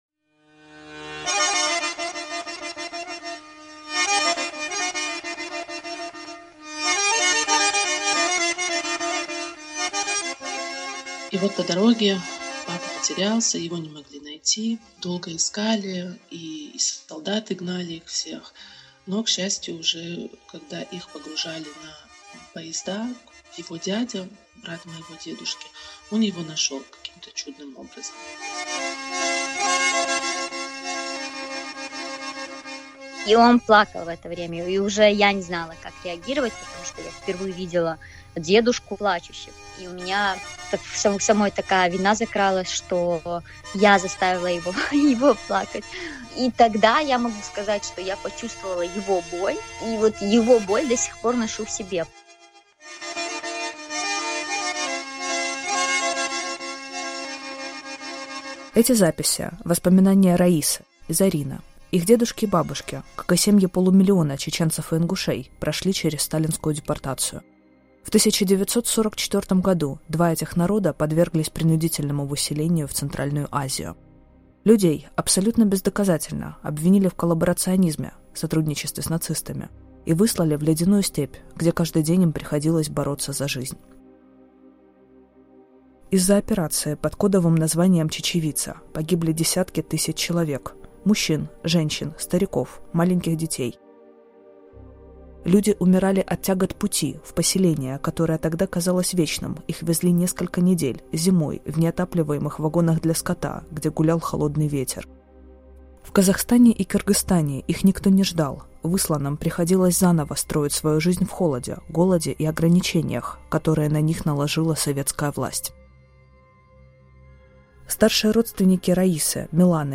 Монологи тех, чьи родители выжили в ссылке. Травма и память поколений, влияние событий 80-летней давности на жизнь сейчас.